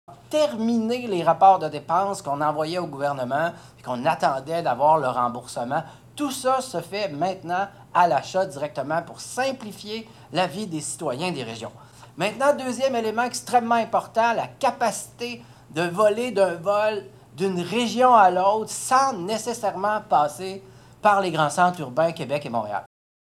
Le député, Yves Montigny, adjoint gouvernemental responsable du transport aérien, apporte des précisions sur ce point et sur un changement majeur concernant les liaisons: